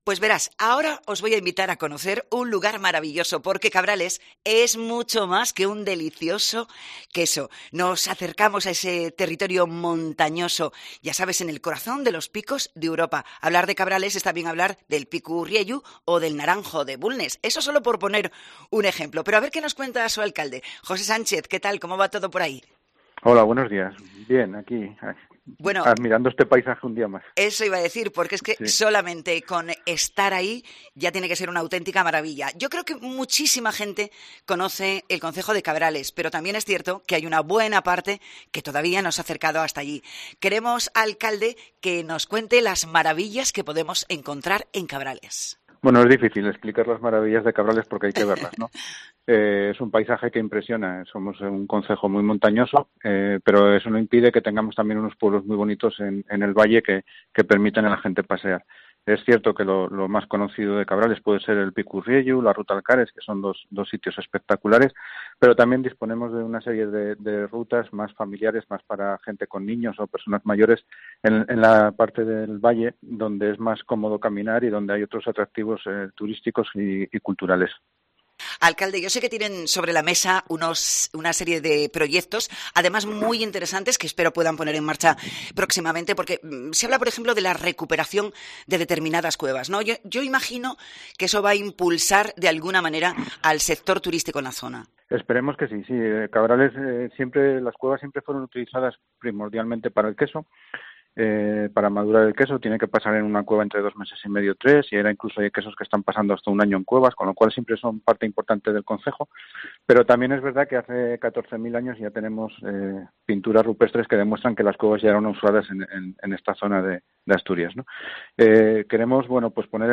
El alcalde cabraliego, José Sánchez, ha pasado por el especial de COPE en la Feria Internacional de Turismo desde IFEMA Madrid
Fitur 2022: Entrevista al alcalde de Cabrales, José Sánchez